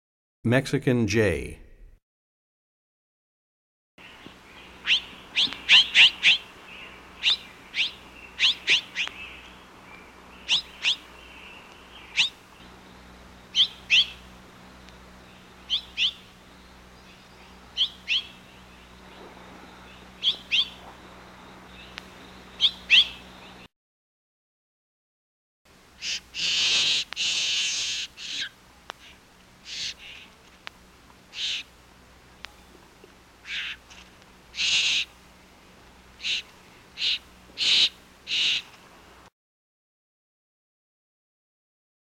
58 Mexican Jay.mp3